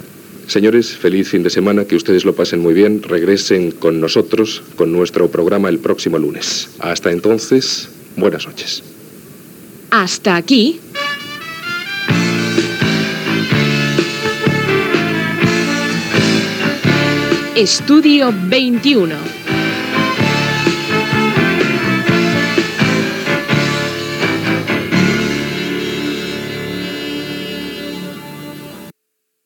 Comiat i careta de sortida del programa.